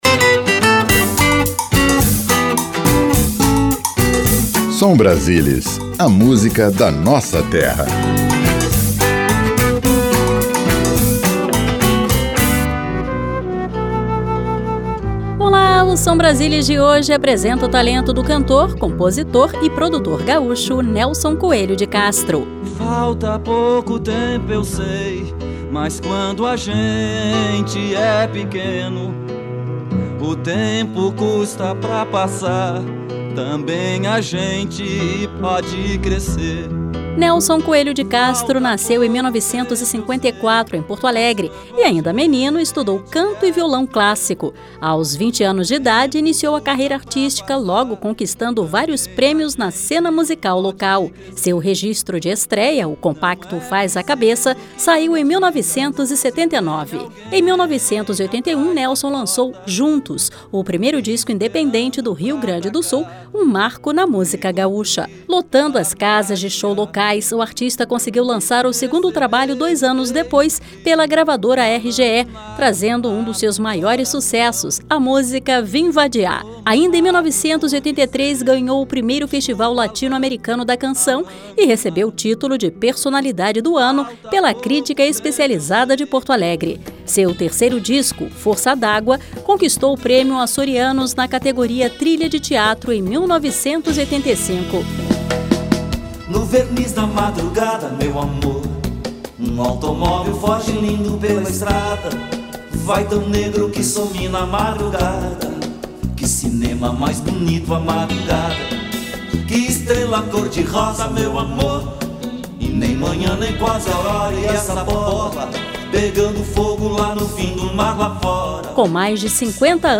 Programete musical que apresenta artistas de cada estado da federação.